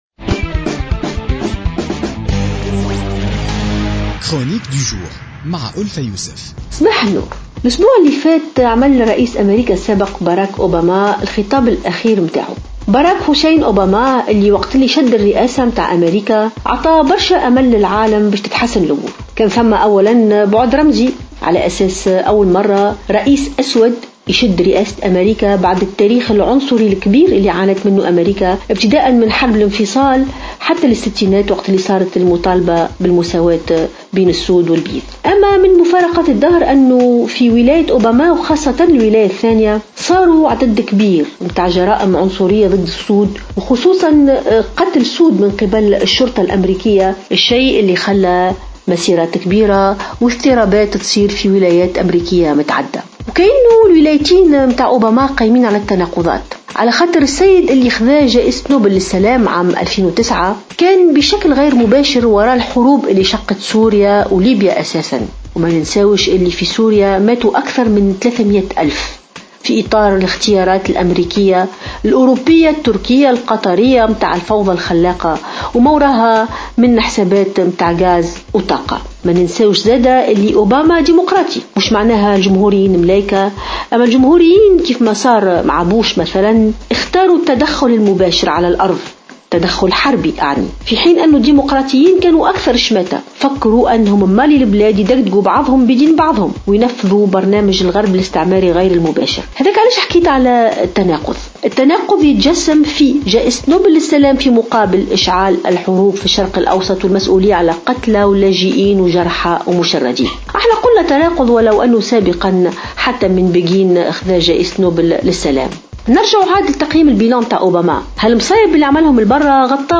تطرقت الكاتبة ألفة يوسف في افتتاحية اليوم الثلاثاء 17 جانفي 2017 إلى تقييم فترتي حكم الرئيس الأمريكي باراك أوباما الذي أعطى أملا للعالم عند توليه الرئاسة لكونه أول رئيس أسود يحكم أمريكا بعد التاريخ العنصري الكبير اللي عانت منه .